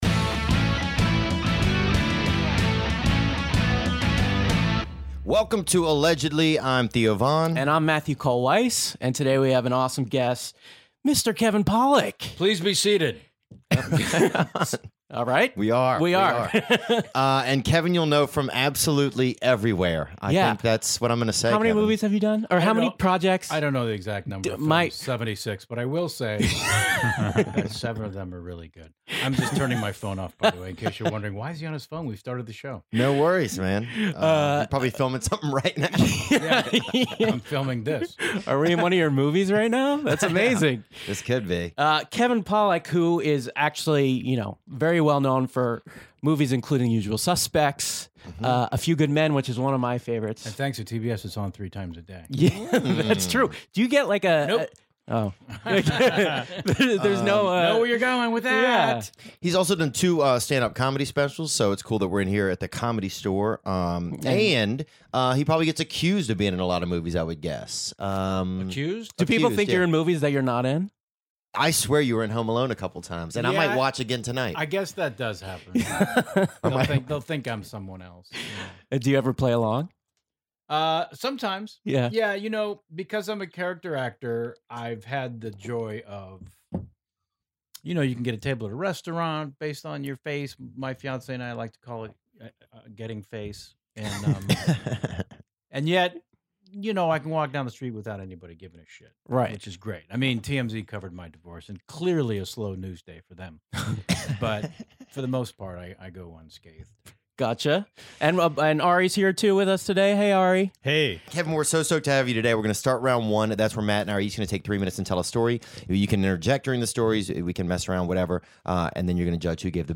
We've got Robert DeNiro in the studio... and Al Pacino... and Liam Neeson... all in the form of actor/comedian Kevin Pollak. The star of such classics as "A Few Good Men" and "The Usual Suspects" lends his talents to the competition this week, judging stories about Christopher Reeve and mushrooms, as well as some unique impressions from the hosts themselves.